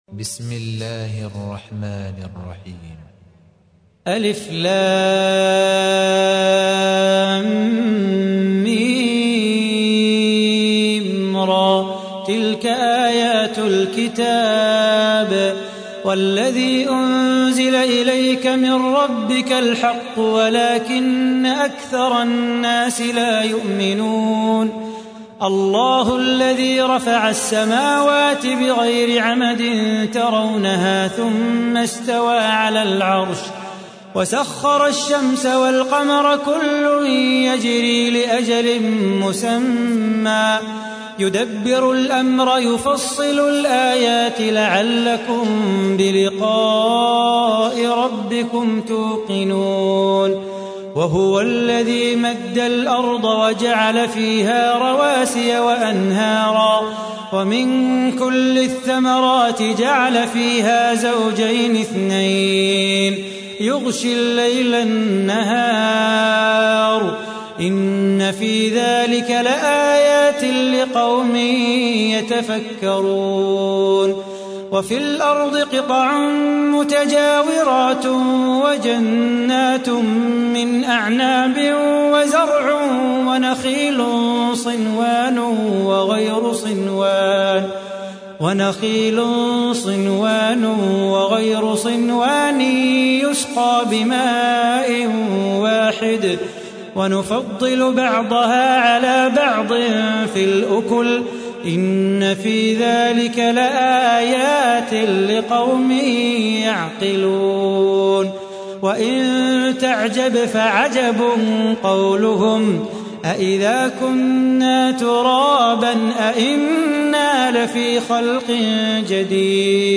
تحميل : 13. سورة الرعد / القارئ صلاح بو خاطر / القرآن الكريم / موقع يا حسين